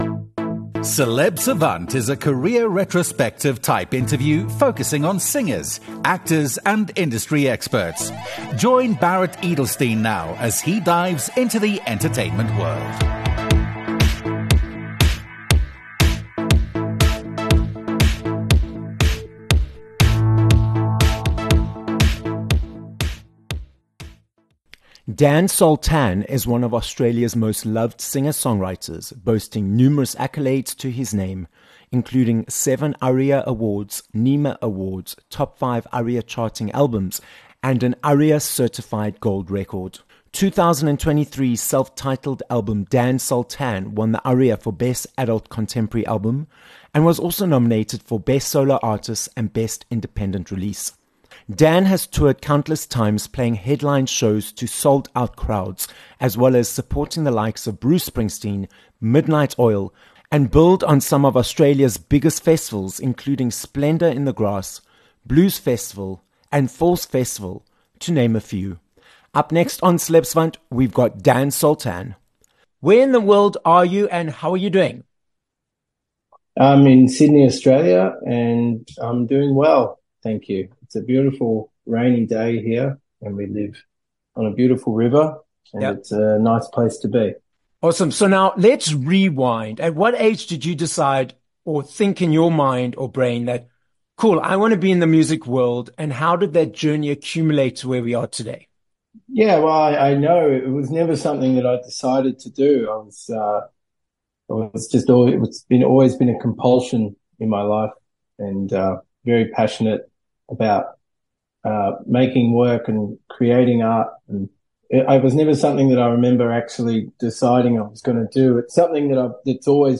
16 Apr Interview with Dan Sultan
Multi ARIA Award-winner, Australian singer and songwriter, Dan Sultan is the guest on this episode of Celeb Savant. Dan explains how he continues to push the goal post as a measurement of success, and he tells us about his multi award-winning career in the music industry.